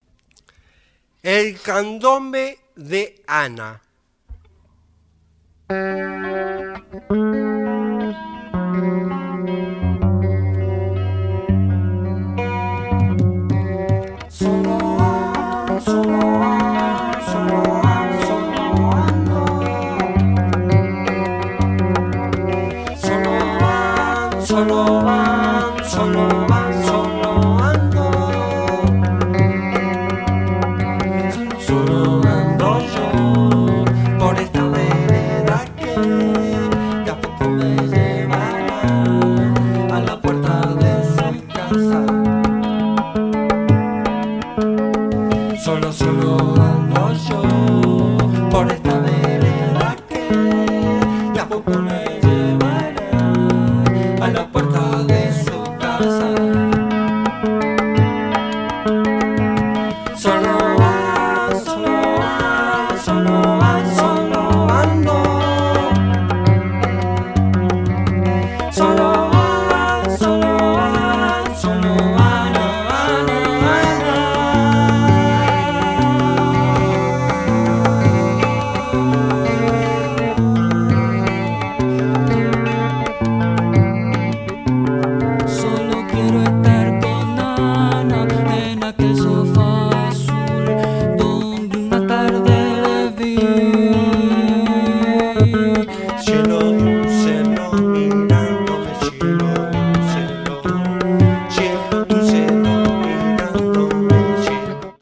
奇妙で幻想的！繊細で詩的！